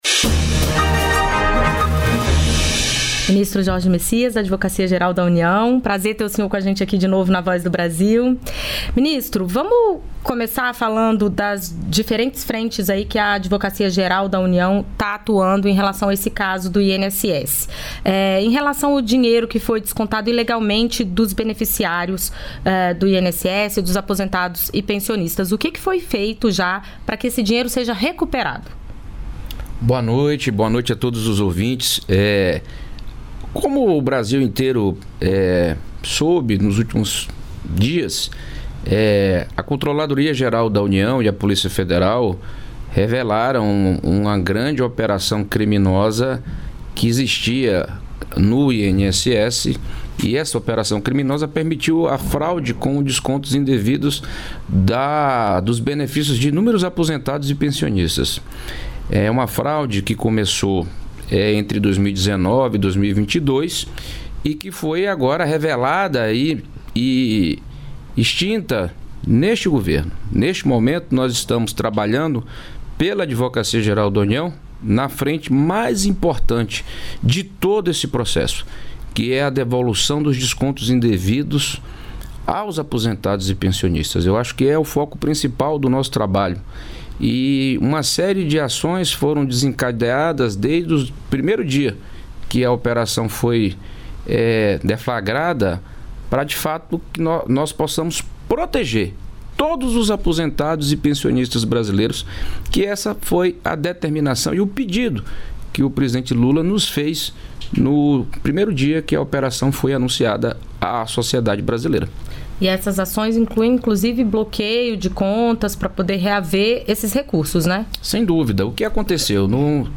Ministro da Previdência Social, Wolney Queiroz
Entrevistas da Voz